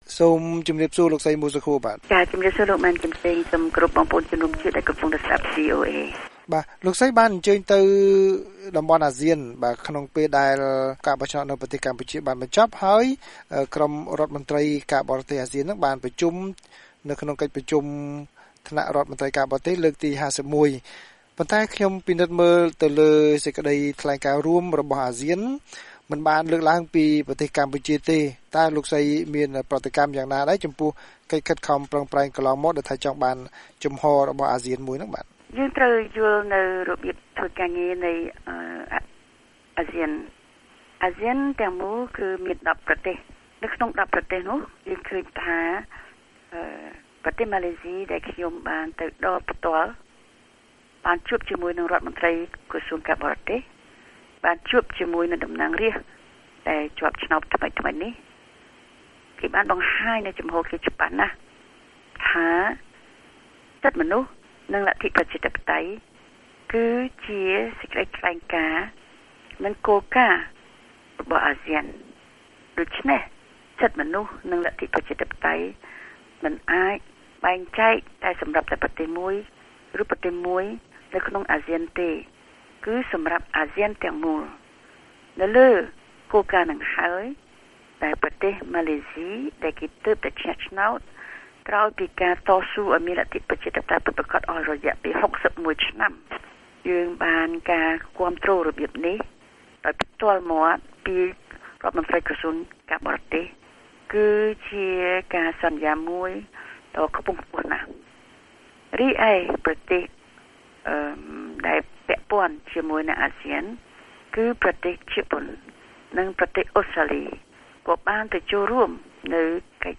បទសម្ភាសន៍ VOA៖ អាស៊ានមិនលើកពីការបោះឆ្នោតនៅកម្ពុជាតែបក្សប្រឆាំងនៅមានក្តីសង្ឃឹម